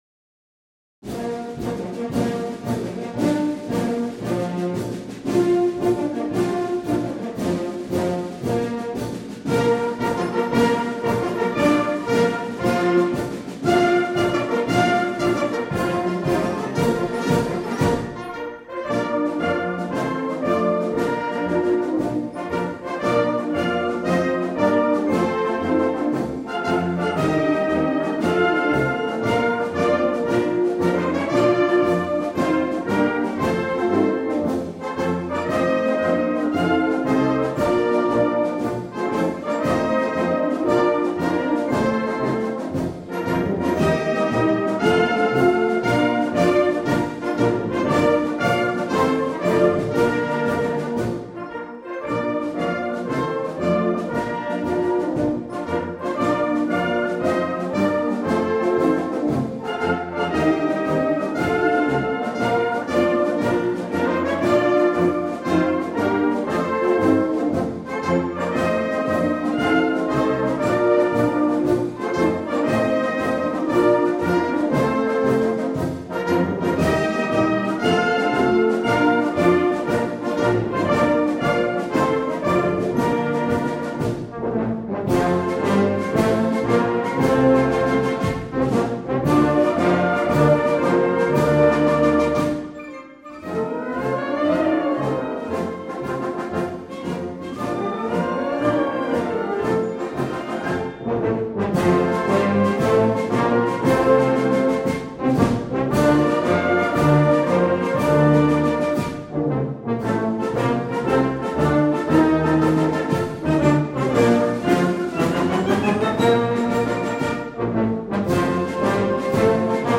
Gattung: Marsch für Blasorchester
Besetzung: Blasorchester